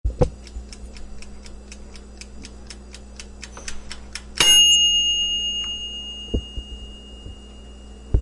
Download Timer sound effect for free.
Timer